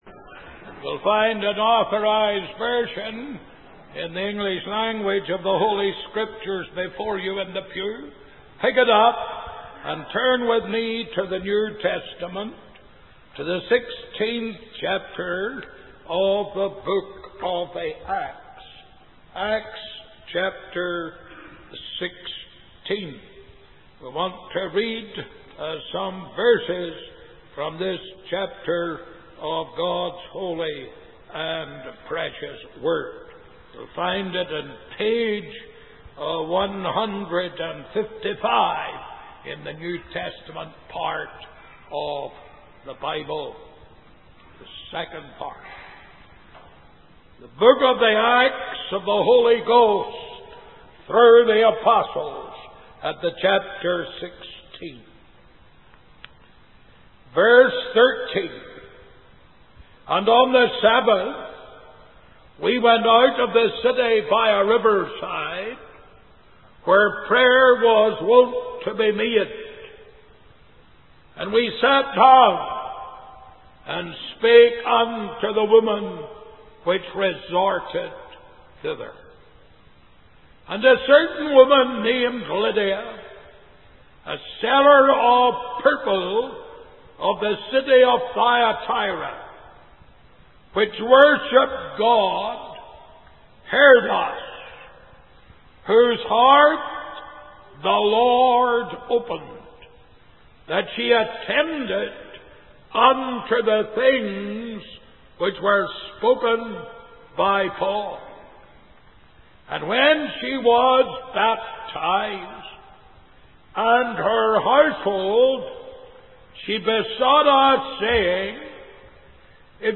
In this sermon, the preacher focuses on the story of the Jailer from the Bible.